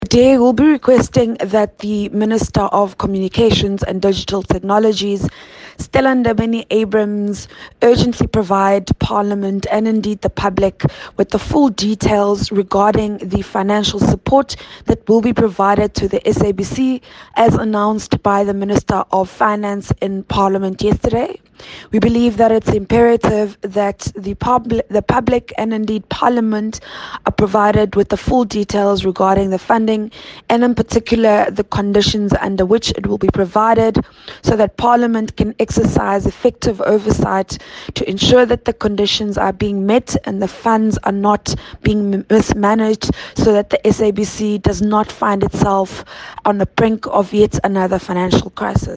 Issued by Phumzile Van Damme MP – DA Shadow Minister of Communications and Digital Technologies
Please find attached a soundbite in
English by Phumzile Van Damme.